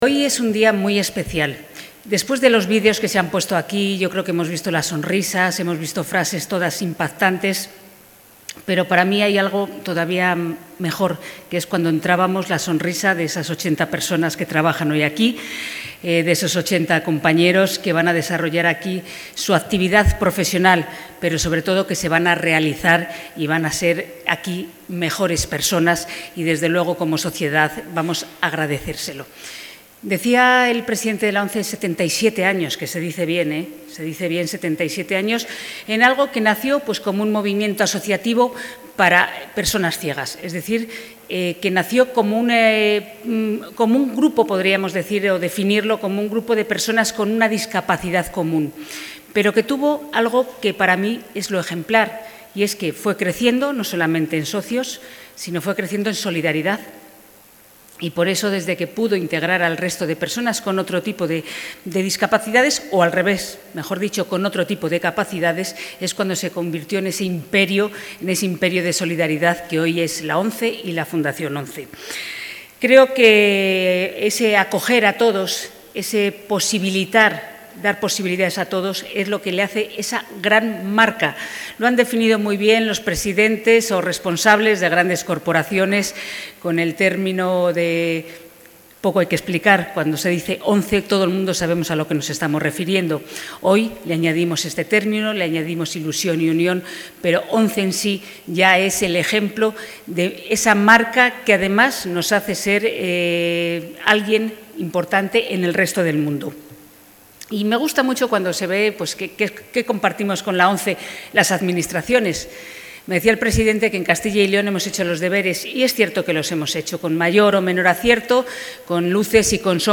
Material audiovisual de la presentación de Ilunion Lavanderías en Castilla y León
Audio intervención Rosa Valdeón.